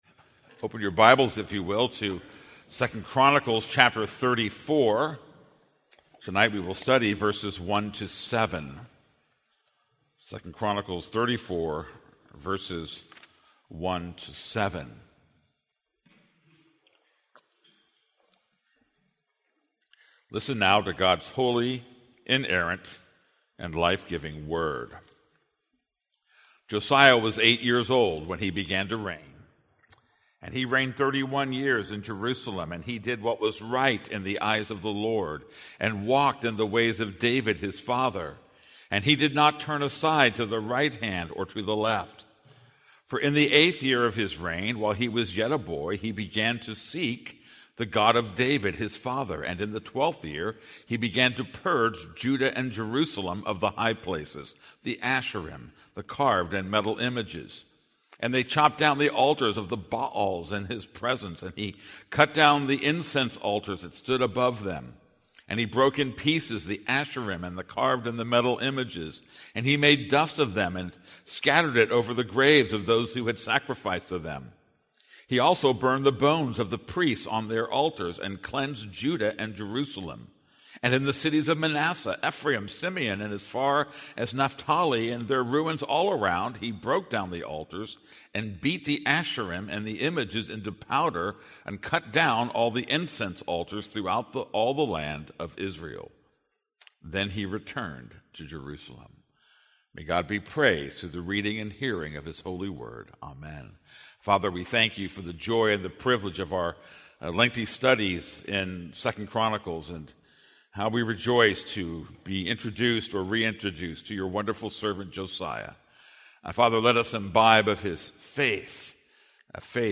This is a sermon on 2 Chronicles 34:1-7.